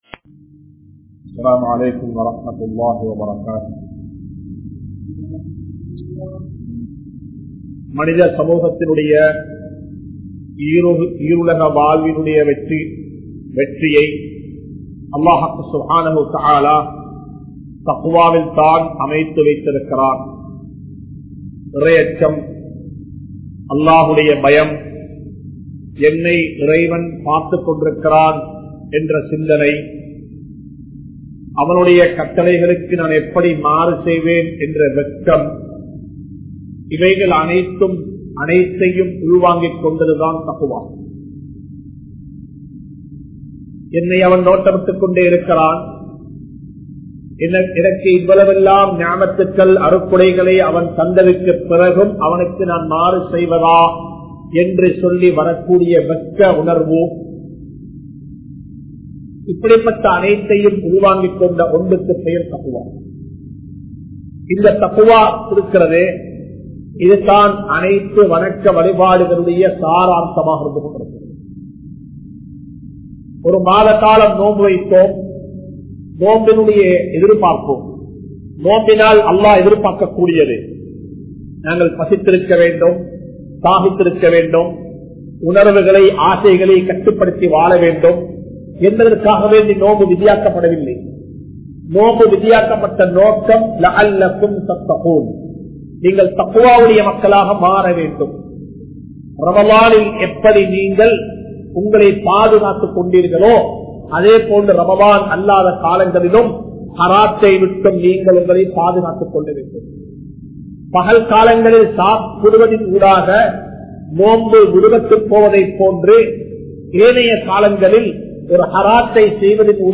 Veatpaalarhale! Muslim Samoohaththai Vitkaatheerhal (வேட்பாளர்களே! முஸ்லிம் சமூகத்தை விற்காதீர்கள்) | Audio Bayans | All Ceylon Muslim Youth Community | Addalaichenai
Colombo 03, Kollupitty Jumua Masjith